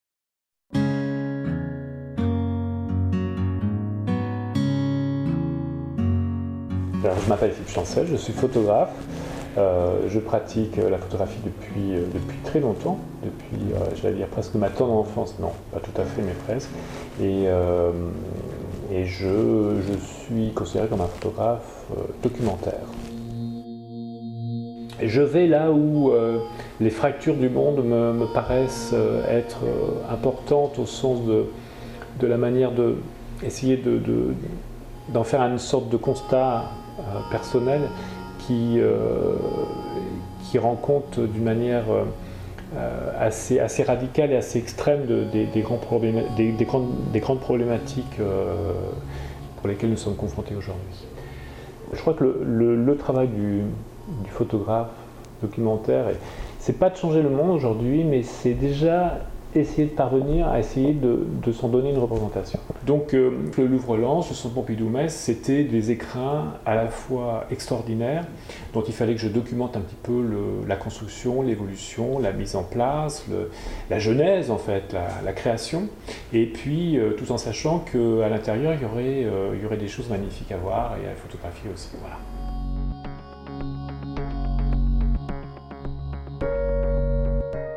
Enquête TV Vendée pour l'émission Comme Toujours
Témoignage